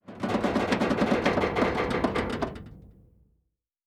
Metal Foley Creak 5.wav